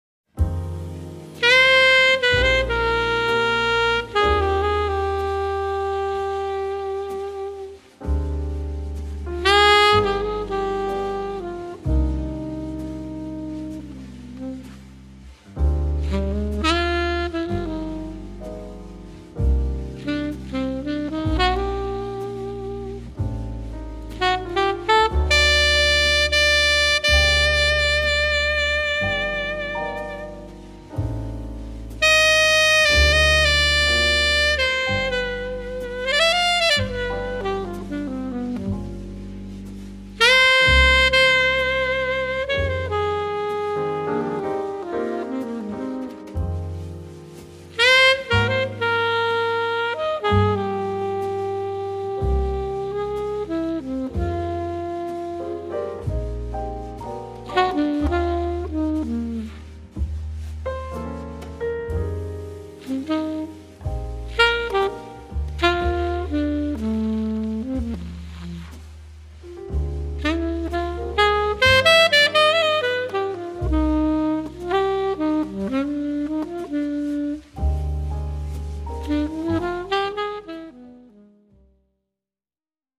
alto saxophone
trumpet
piano / Rhodes
bass
drums